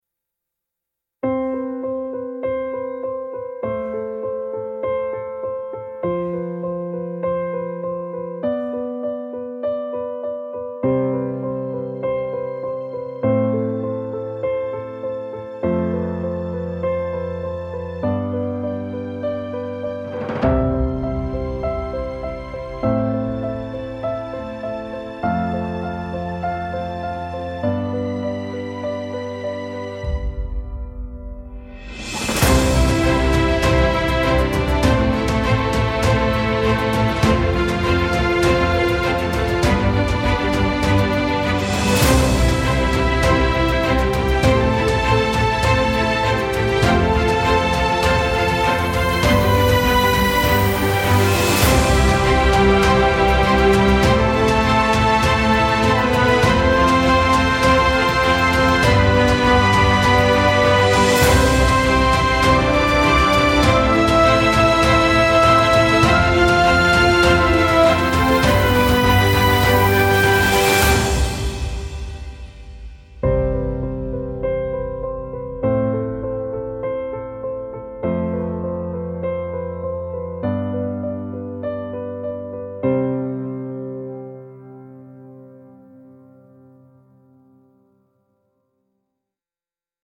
epic cinematic orchestral piece with slow dramatic build and sweeping strings